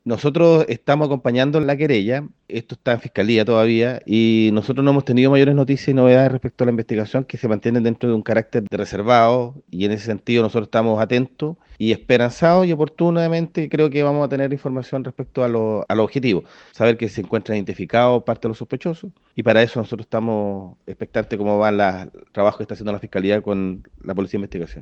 El delegado, Jorge Alvial, dijo que parte de los sospechosos ya están identificados, esperanzados en que se cumplan los objetivos de la investigación.